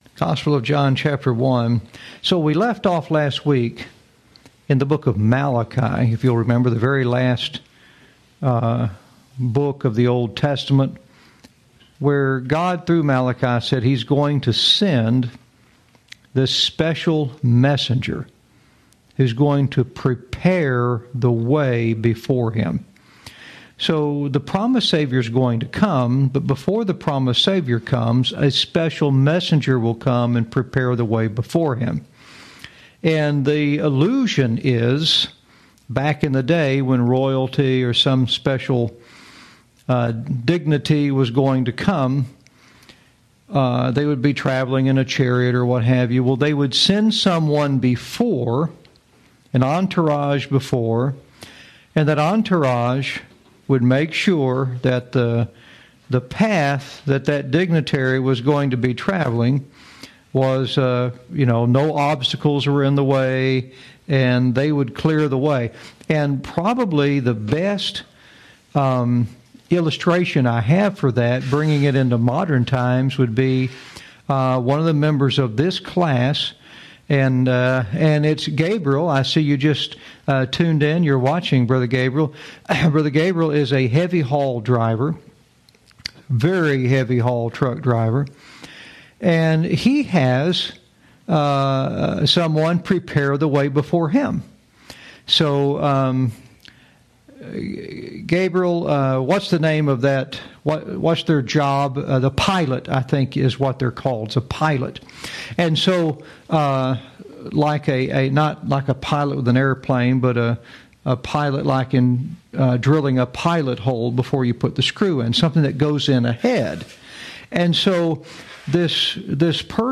Lesson 32